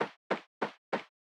Footsteps2a.wav